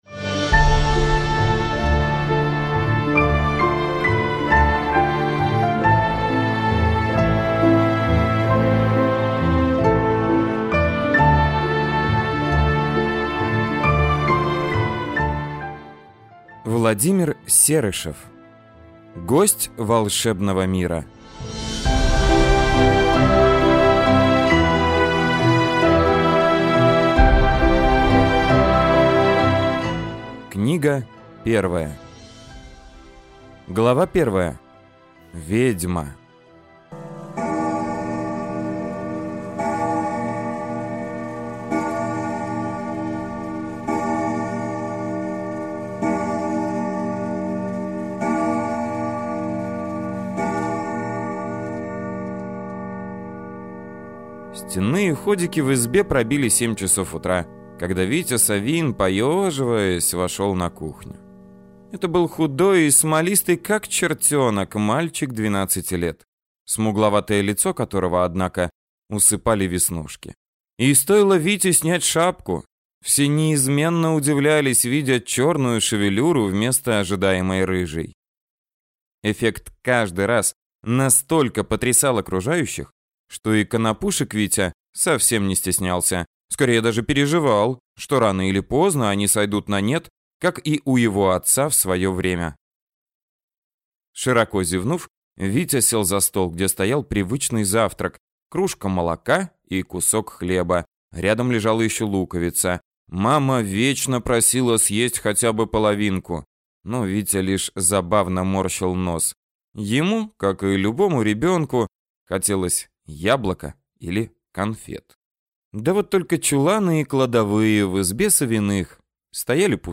Аудиокнига Гость волшебного мира. Книга 1 | Библиотека аудиокниг